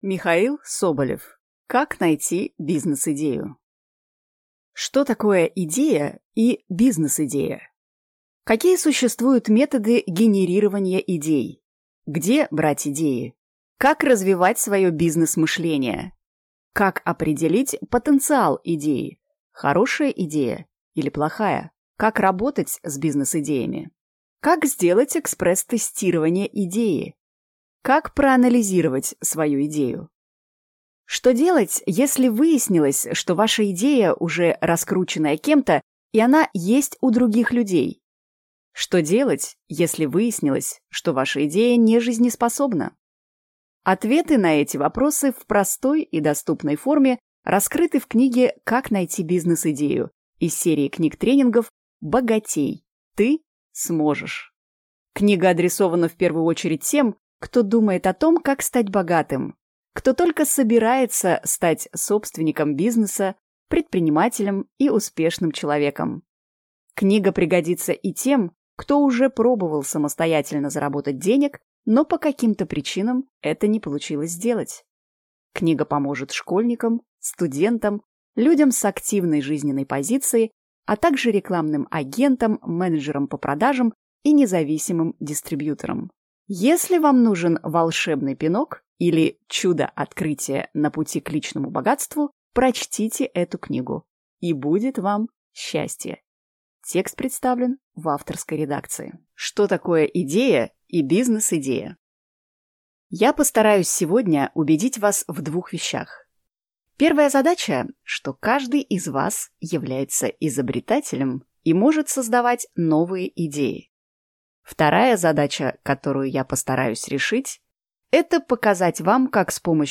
Аудиокнига Как найти бизнес-идею?